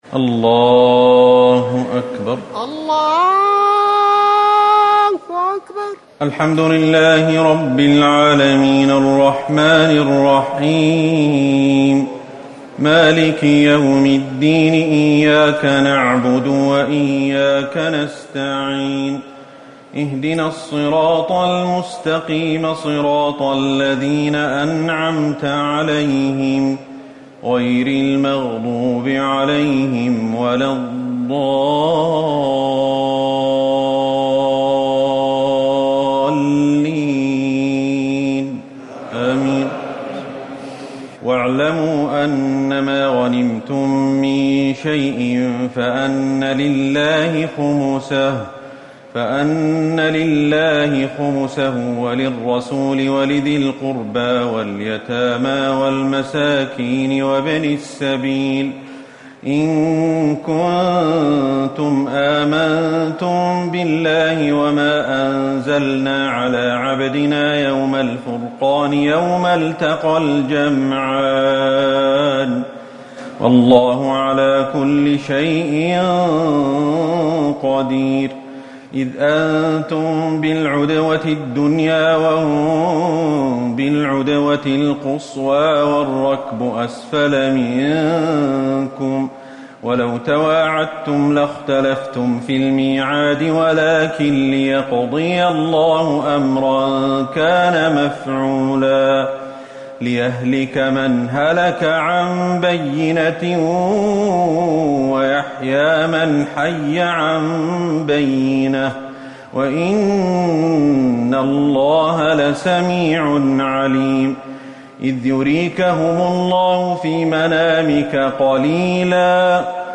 ليلة ٩ رمضان ١٤٤٠ من سورة الأنفال ٤١- ٣٣ و التوبة ١-٣٣ > تراويح الحرم النبوي عام 1440 🕌 > التراويح - تلاوات الحرمين